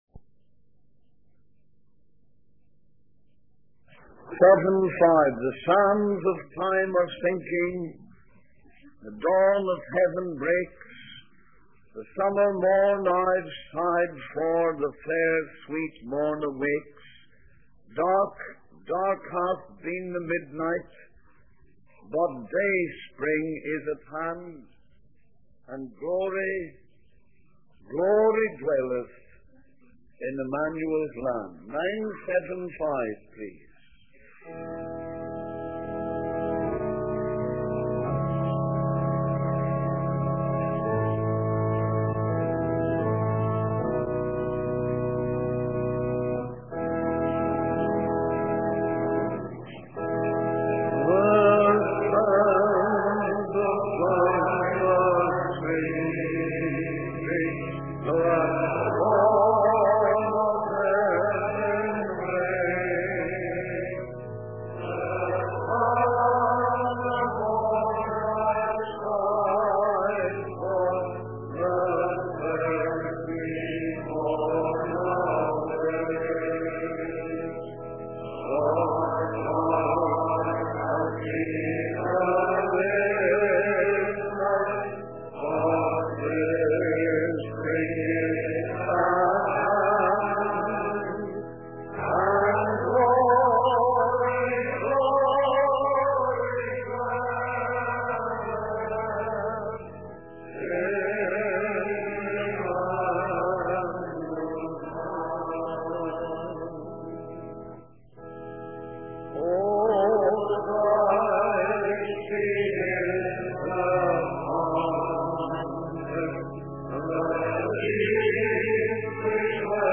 In this sermon, the preacher emphasizes the importance of understanding the book of Revelation.